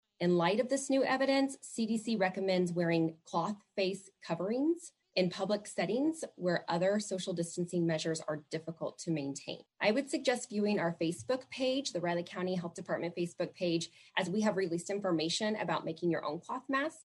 During the daily Riley County Health Department press conference, Riley County Health Director Julie  Gibbs gave an update on the status of COVID-19 in Manhattan.